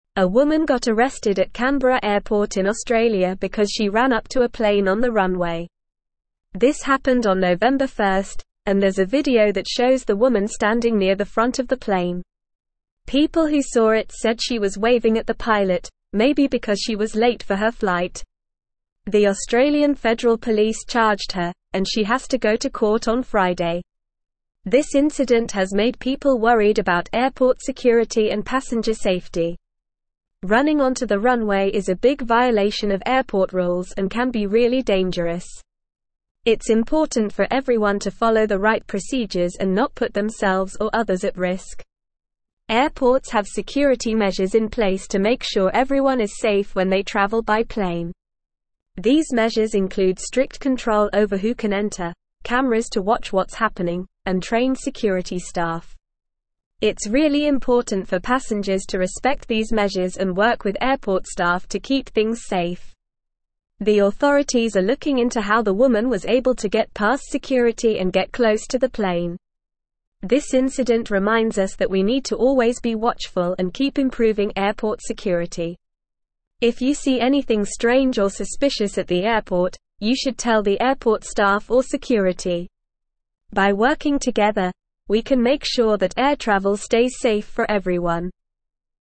Normal
English-Newsroom-Upper-Intermediate-NORMAL-Reading-Woman-Arrested-for-Running-onto-Tarmac-at-Canberra-Airport.mp3